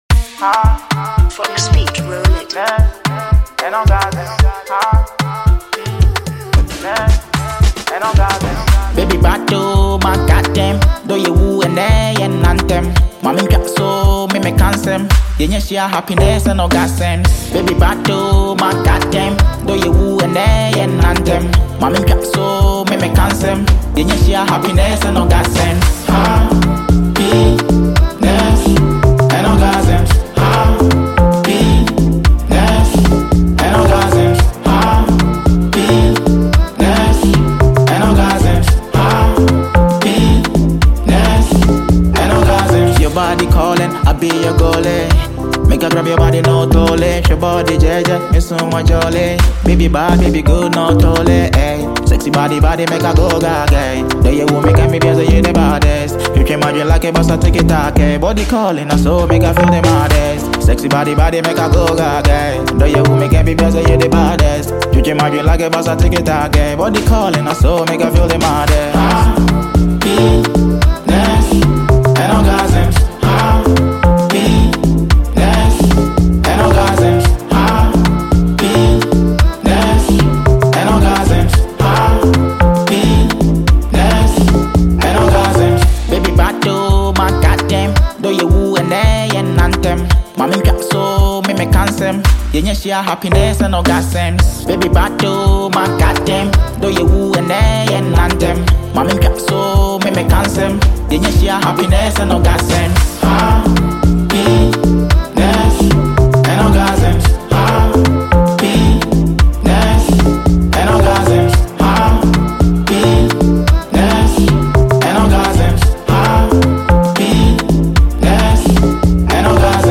infectious beat
smooth vocals
With its catchy chorus and uplifting lyrics